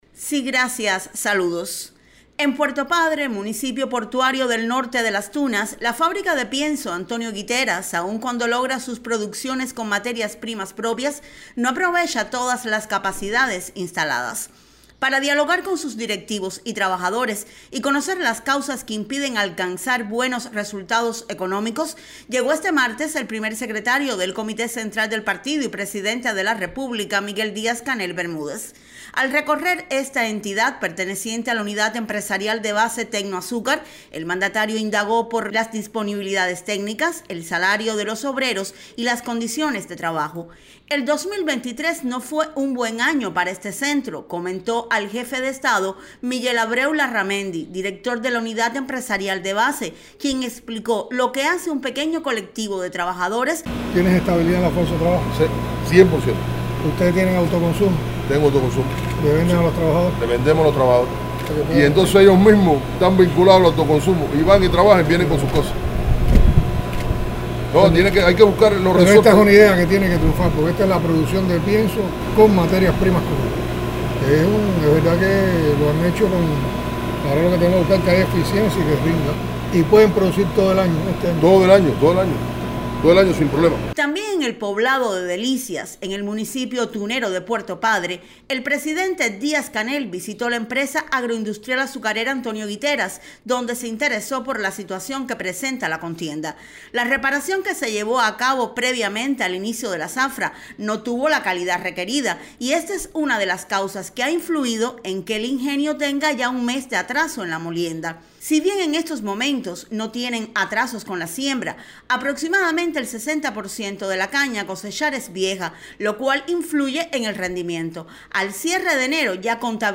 El Primer Secretario del Comité Central del Partido Comunista y Presidente de la República continúo este martes los recorridos por municipios del país para intercambiar con directivos y trabajadores de entidades que no alcanzan buenos resultados económicos. En la jornada matutina, acompañado por el Secretario de Organización del Comité Central, visitó el municipio de Puerto Padre, en Las Tunas.
dc_en_puerto_padre_las_tunas.mp3